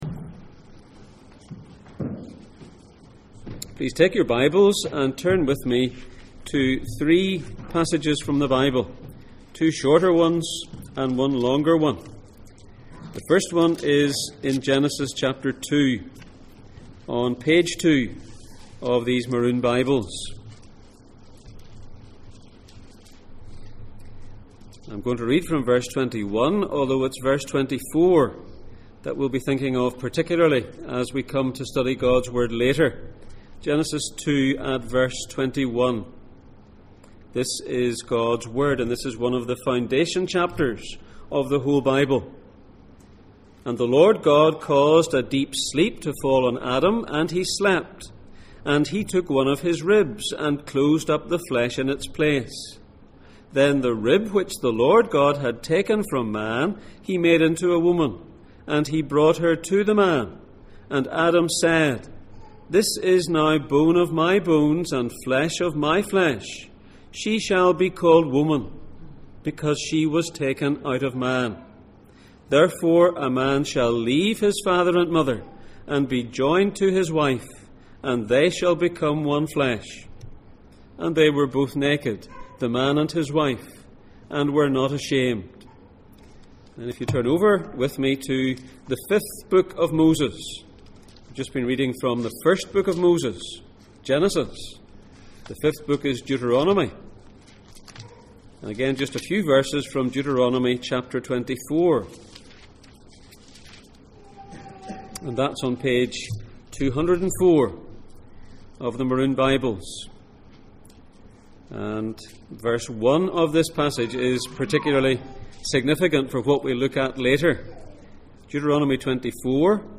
Jesus in Mark Passage: Mark 10:1-12, Genesis 2:21-24, Deuteronomy 24:1-4, Ephesians 5:22-25 Service Type: Sunday Morning